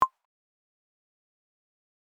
決定ボタン03 - 音アリー